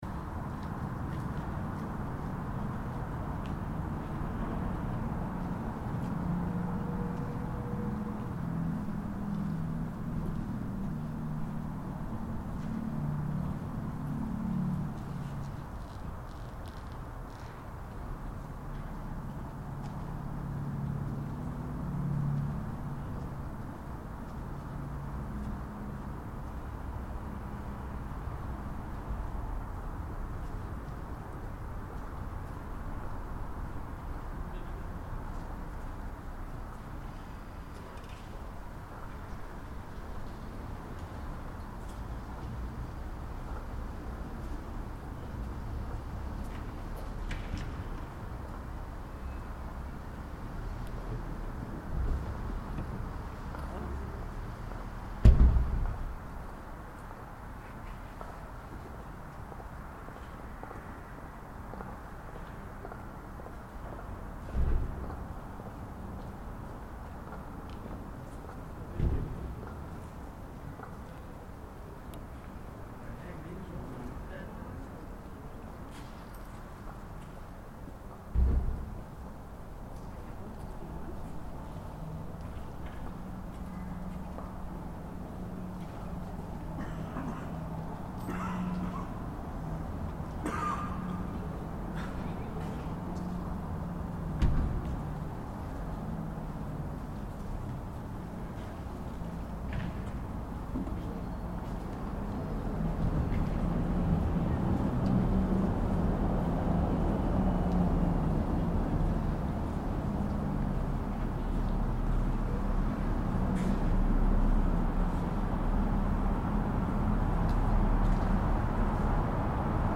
This audio recording, captured in front of the church in Christiansfeld, Southern Denmark on the morning of Christmas Eve 2024, documents congregants arriving for the 7:30 AM church service.